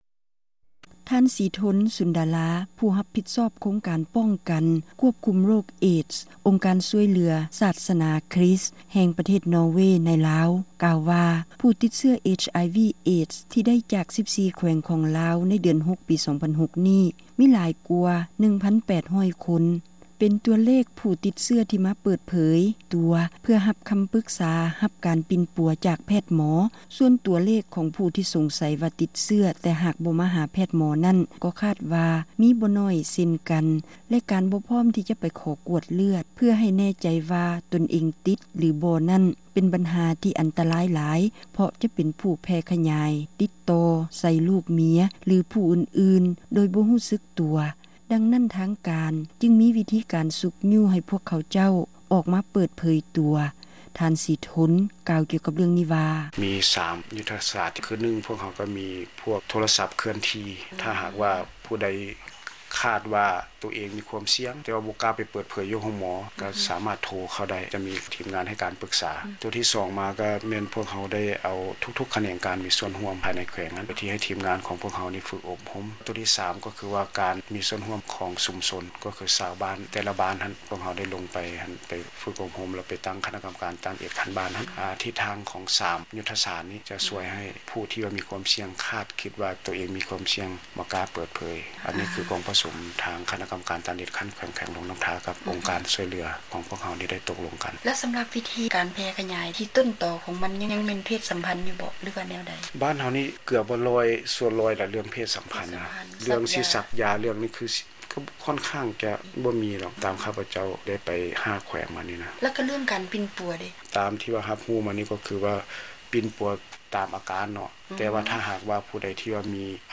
ສຳພາດ ໂດຍ